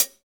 Index of /90_sSampleCDs/Roland L-CD701/KIT_Drum Kits 4/KIT_Hard Core
HAT HATSO0AR.wav